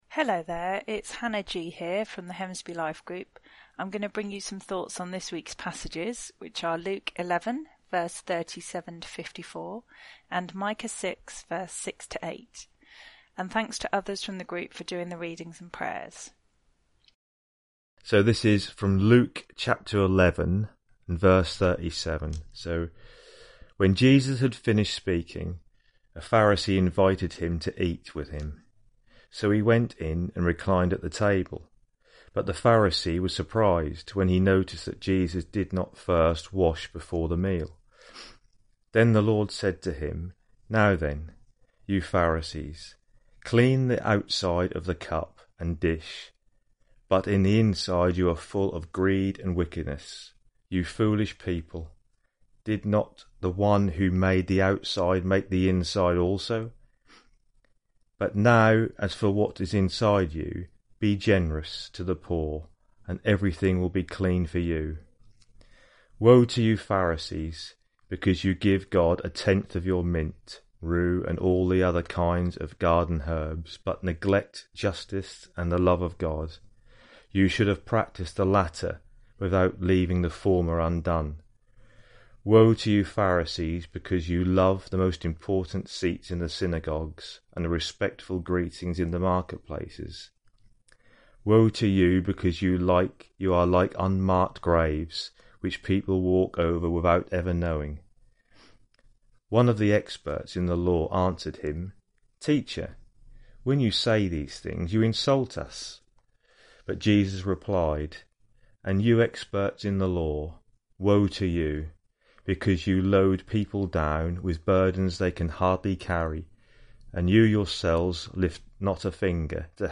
Preacher: Hemsby Lifegroup